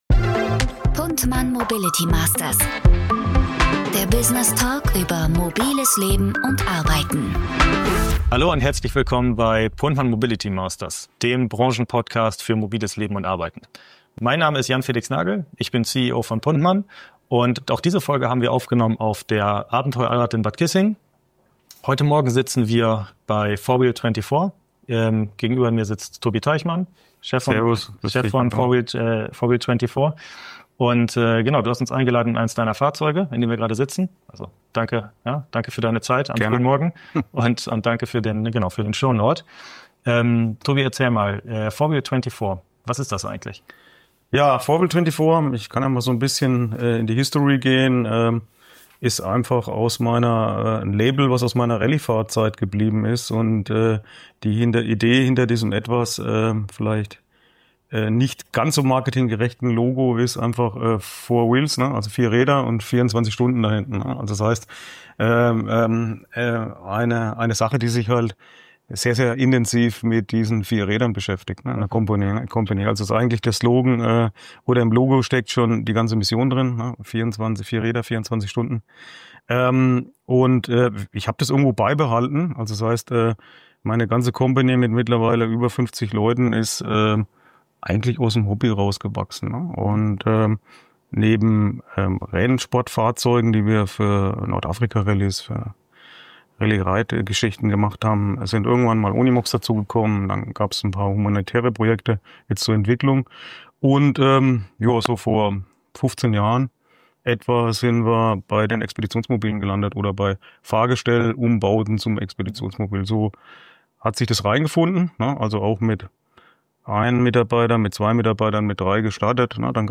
Wir treffen uns auf der Abenteuer & Allrad in Bad Kissingen – und zwar direkt in einem Expeditionsmobil von 4Wheel24.